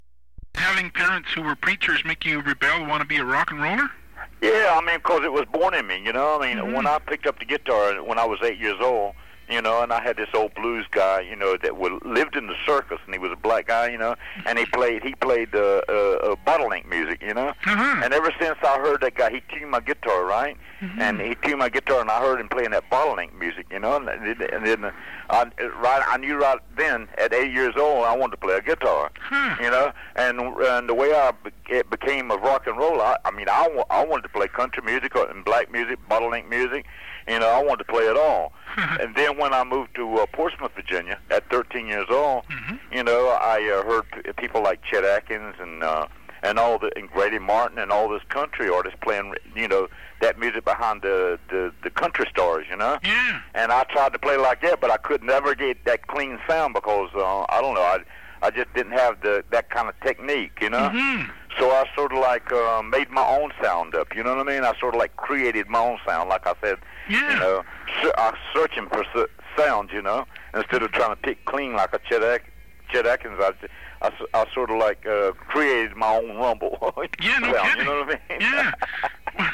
I’ve been doing my part to try and spread the word on Link by releasing audio excerpts from that interview I did, and here’s the latest.